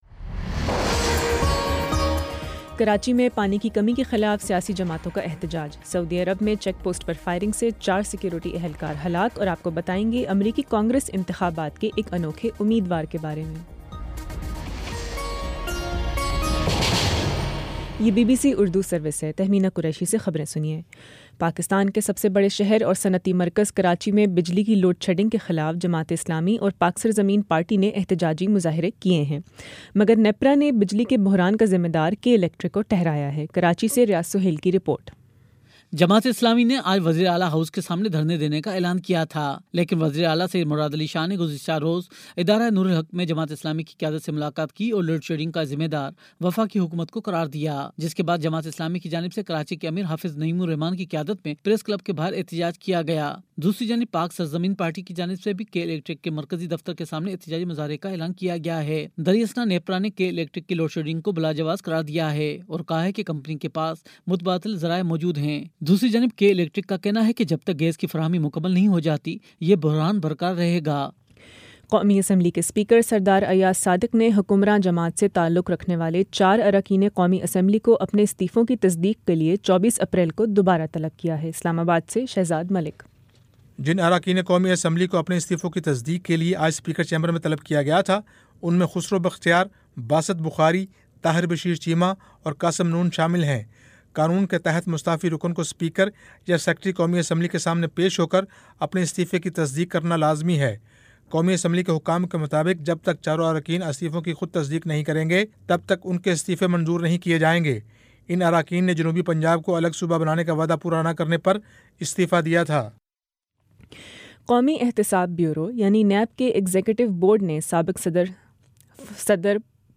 اپریل 20 : شام چھ بجے کا نیوز بُلیٹن